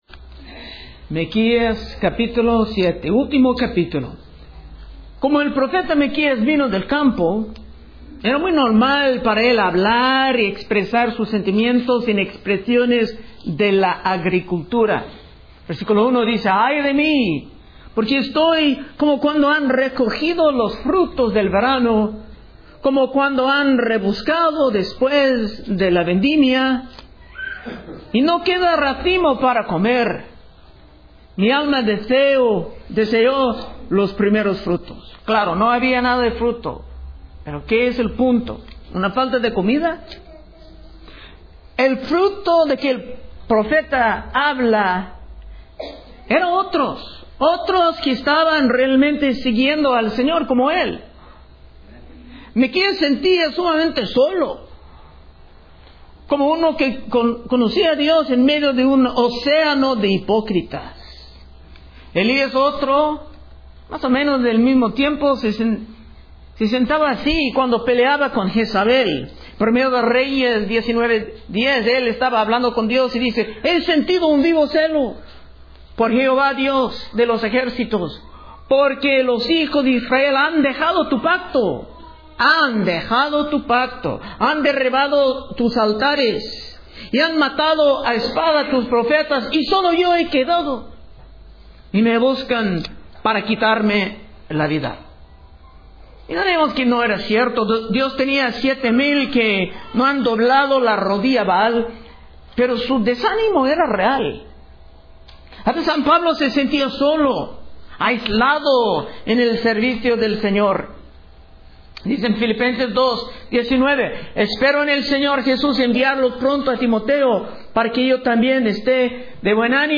Predicaciones De Exposición Libro De Miqueas